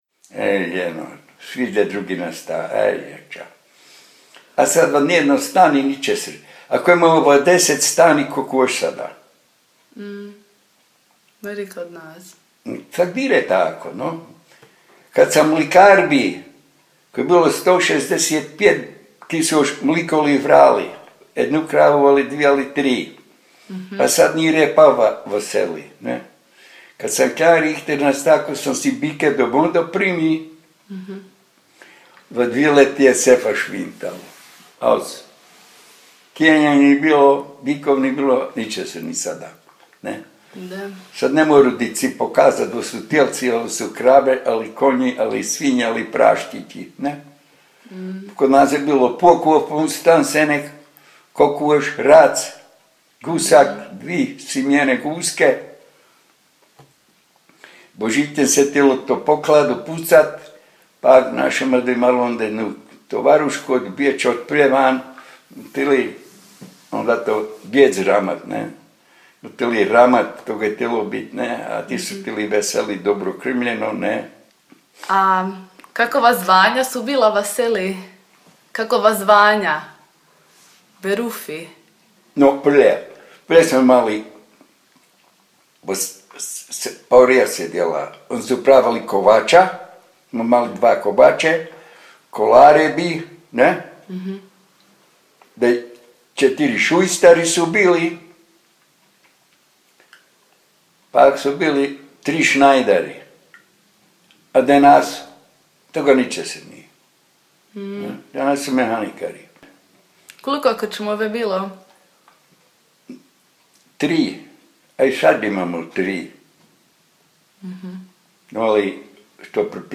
Mali Borištof – Govor
25_Mali-Boristof_govor.mp3